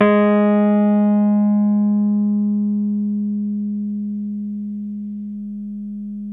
RHODES CL09R.wav